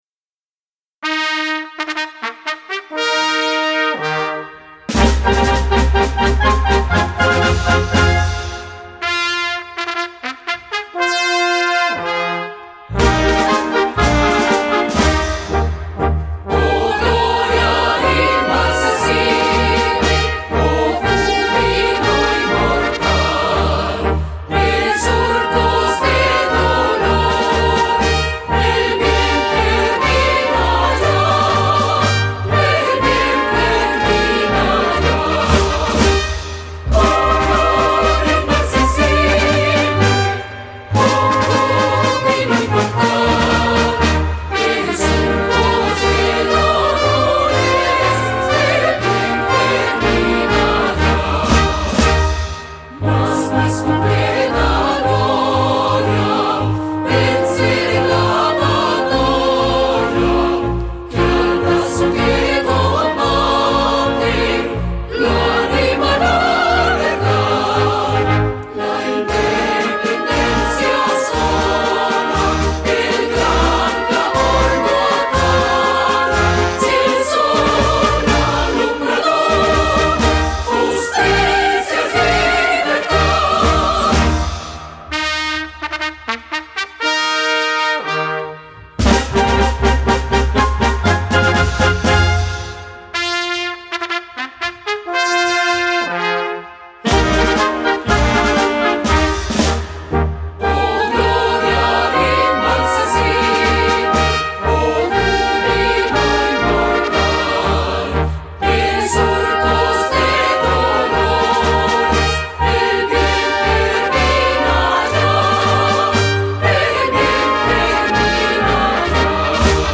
Государственный гимн Колумбии. Скачать MP3: инструментальный,